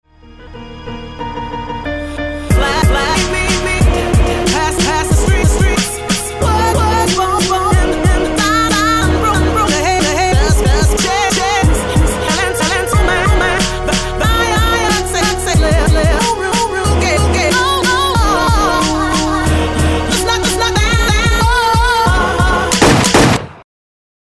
Так же пожеланию вставляю в минуса капы)))) вот пример))) (на припев)